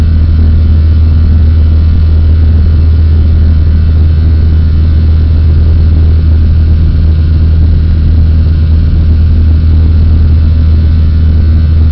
cfmhumfull-wingfwd.wav